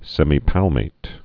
(sĕmē-pălmāt, -pä-, -pälmāt, sĕmī-) also sem·i·pal·mat·ed (-mātĭd)